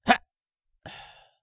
CK起身2.wav
CK起身2.wav 0:00.00 0:01.45 CK起身2.wav WAV · 125 KB · 單聲道 (1ch) 下载文件 本站所有音效均采用 CC0 授权 ，可免费用于商业与个人项目，无需署名。
人声采集素材/男2刺客型/CK起身2.wav